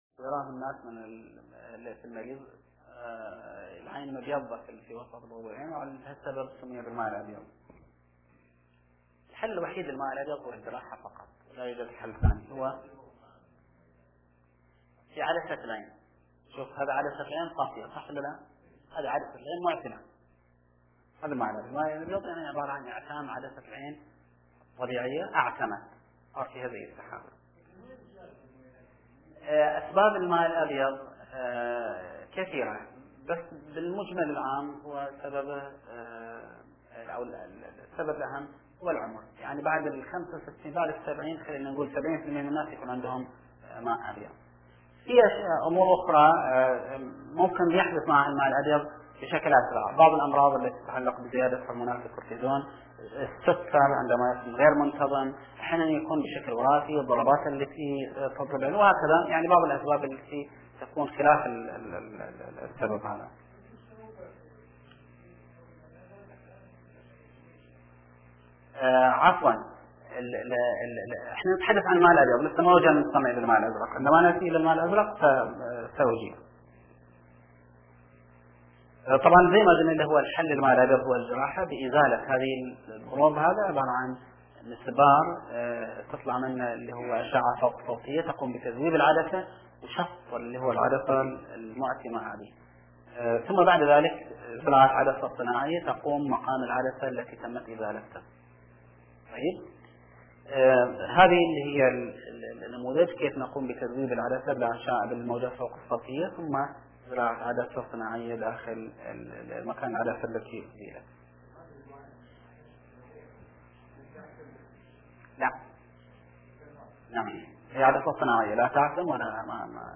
وفيما يلي التغطية المصورة مع التسجيل الصوتي للمحاضرة: